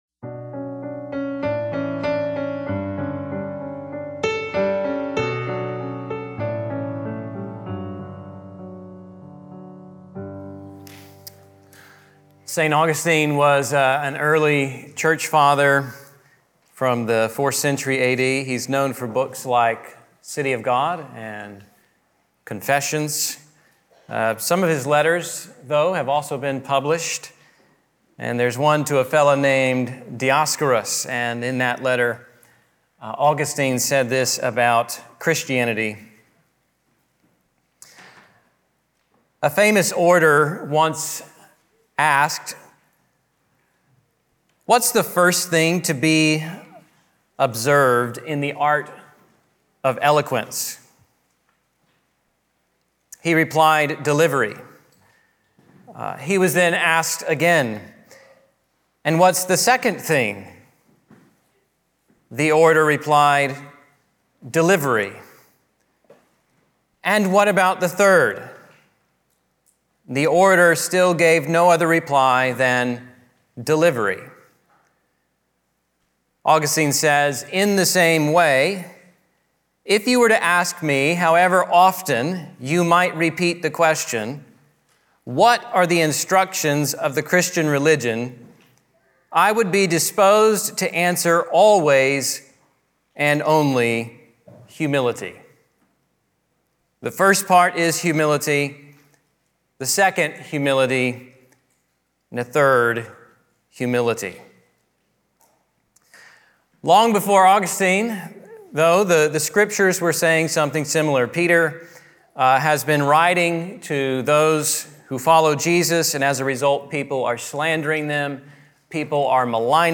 Sermon on 1 Peter 5:1–5